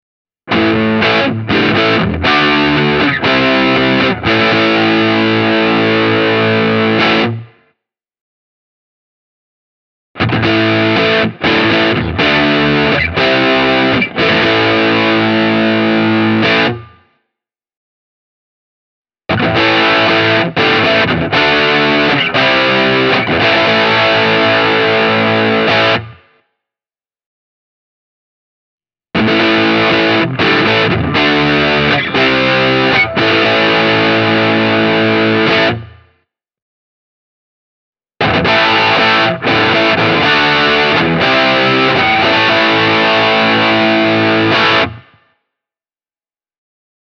The Yamaha’s clean and transparent acoustic character translates into a healthy amplified tone.
Both soundbites start with the neck singlecoil, then switch to both pickups with the humbucker split, and lastly to the split humbucker on its own. The last two motifs are the combined setting, followed by the bridge pickup on its own, both with the full humbucker switched on:
Yamaha Pacifica 611H – clean